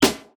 Draw in a curve that leaves the nice parts alone while cutting out the wooshing sound.